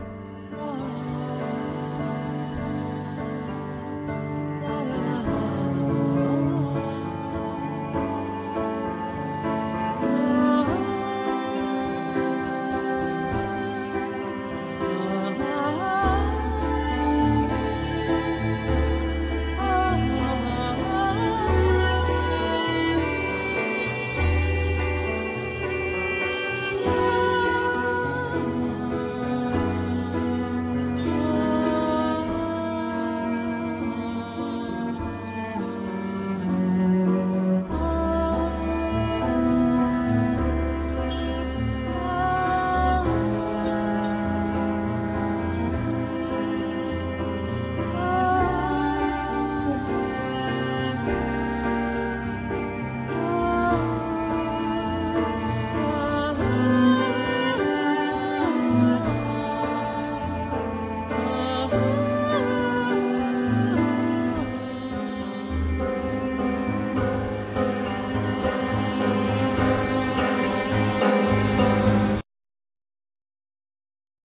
Drums, Percussions
Voice
Piano
Cello